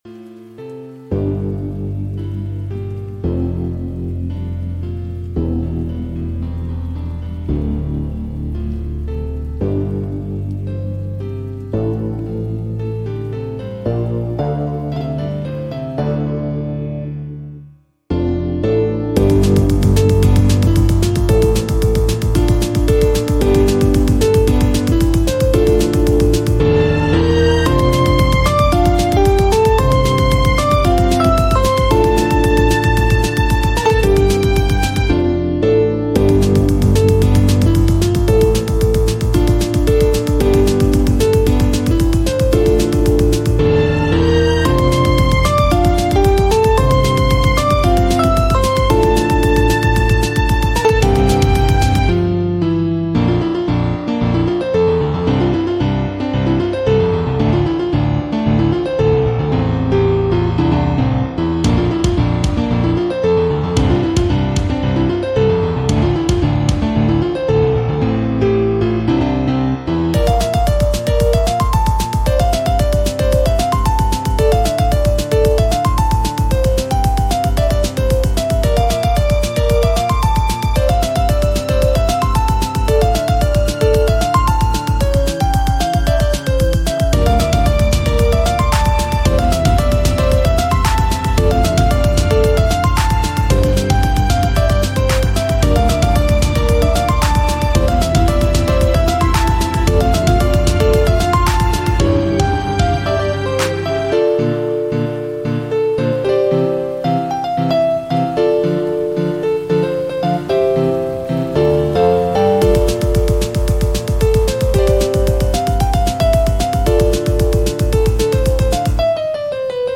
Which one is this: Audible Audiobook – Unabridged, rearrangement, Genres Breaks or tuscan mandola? rearrangement